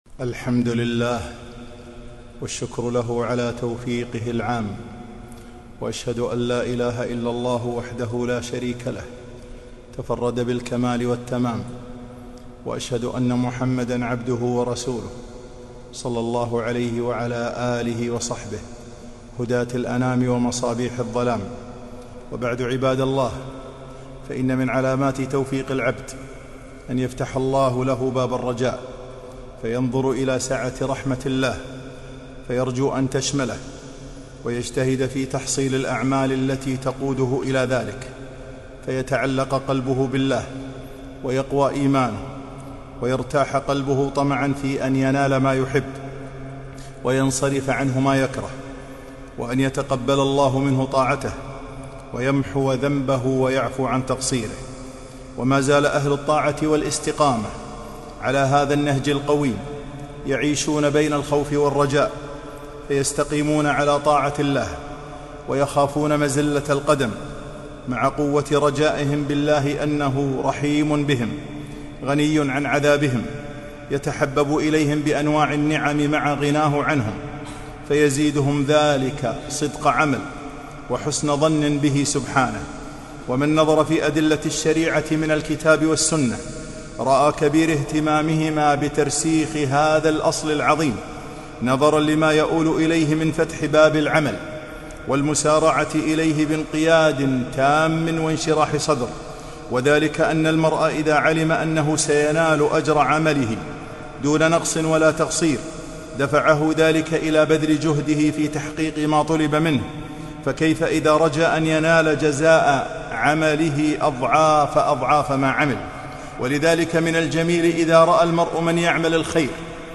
خطبة - الرجاء